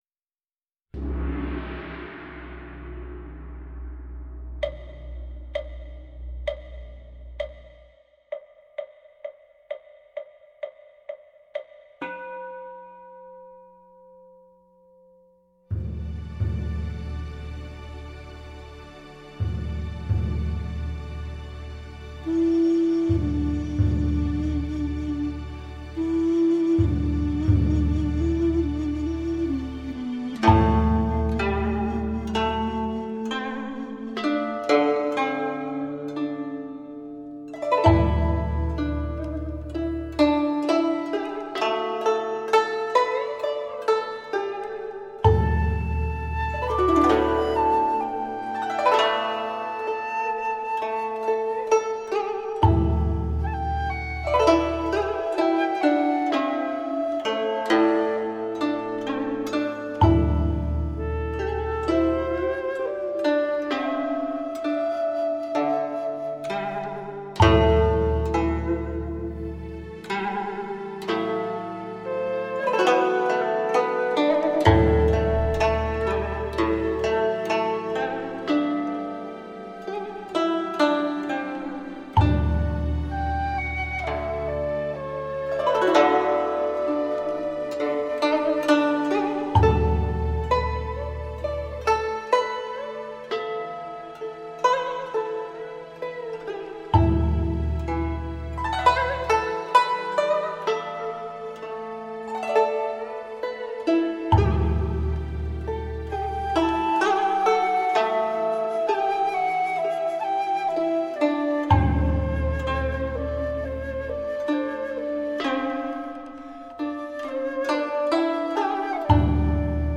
Tradícionális kínai zene